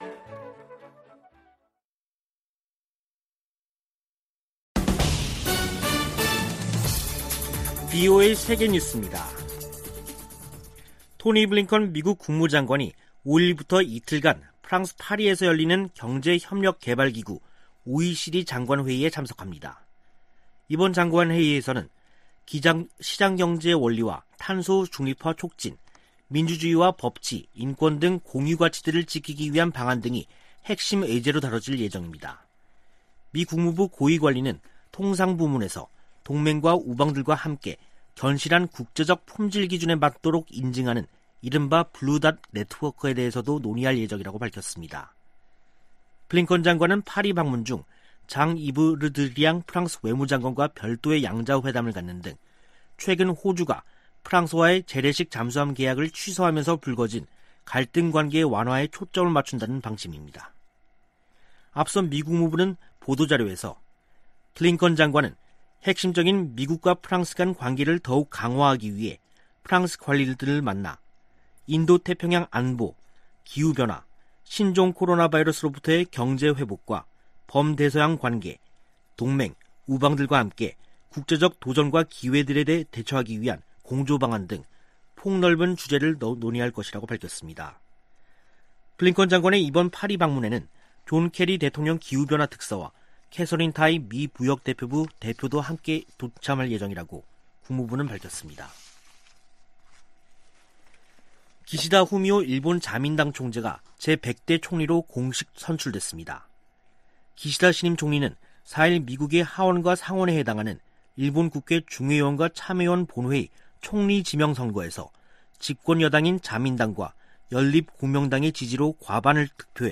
VOA 한국어 간판 뉴스 프로그램 '뉴스 투데이', 2021년 10월 4일 3부 방송입니다. 북한이 일방적으로 단절했던 남북 통신연락선을 복원했습니다. 유엔 안보리 비공개 회의에서 북한의 최근 '극초음속’ 미사일 발사에 관해 논의했습니다. 미국은 북한과의 대화와 관련해 구체적인 제안을 했지만 답변을 받지 못했다고 밝혔습니다.